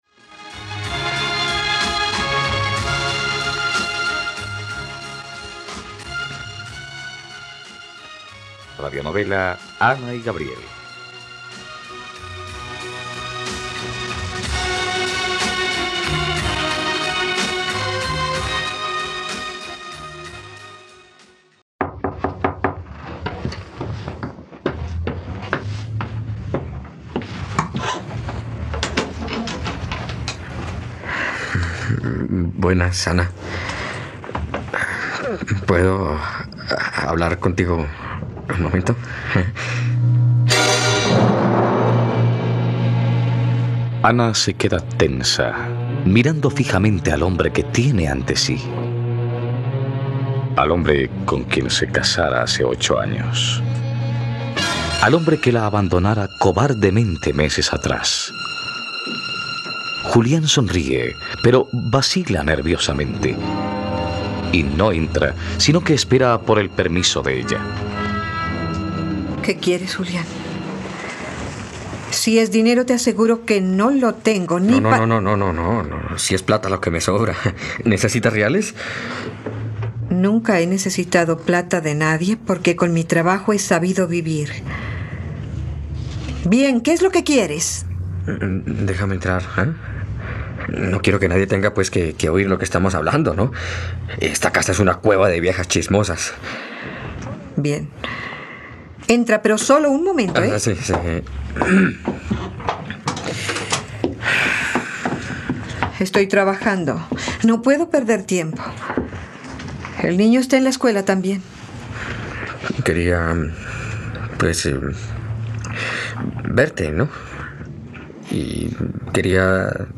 ..Radionovela. Escucha ahora el capítulo 56 de la historia de amor de Ana y Gabriel en la plataforma de streaming de los colombianos: RTVCPlay.